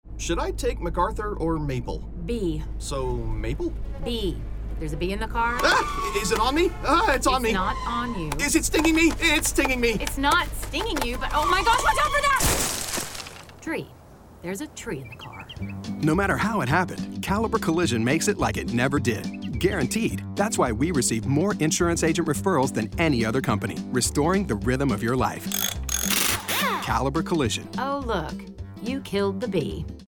Middle Aged
Commercial